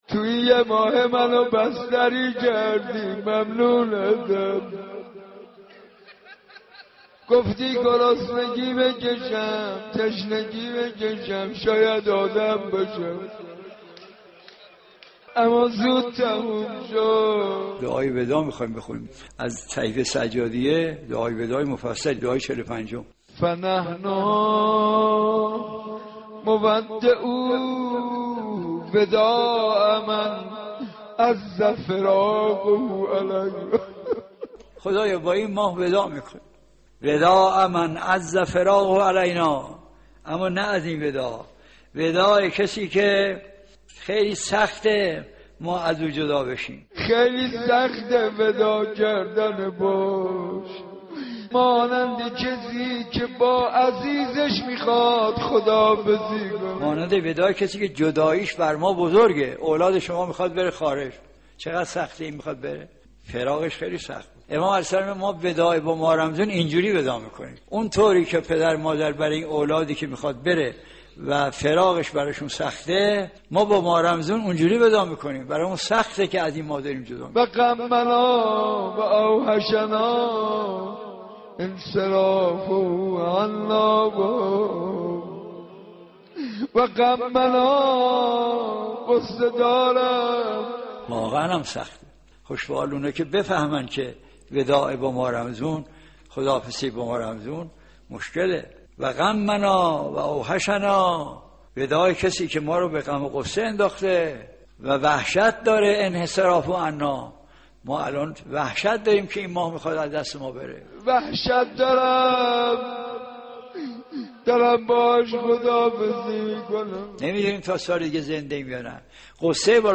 مداحی و مناجات وداع با ماه رمضان حاج منصور ارضی | ❤یک نت